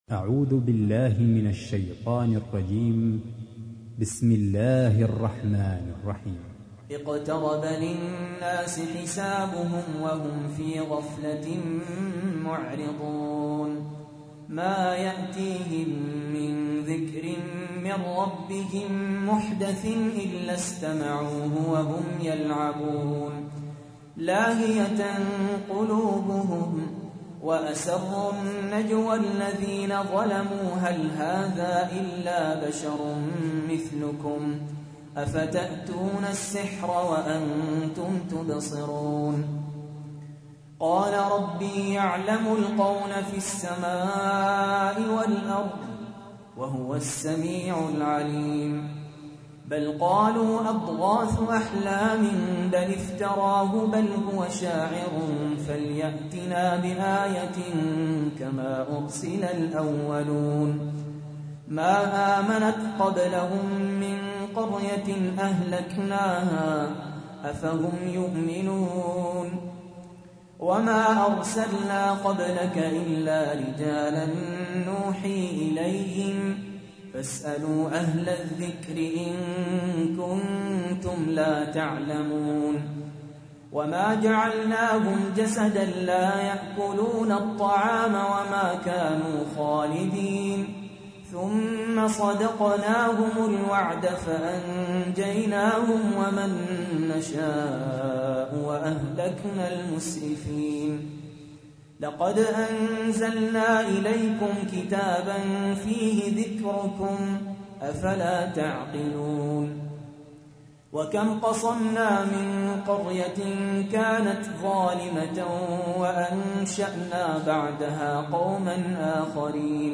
تحميل : 21. سورة الأنبياء / القارئ سهل ياسين / القرآن الكريم / موقع يا حسين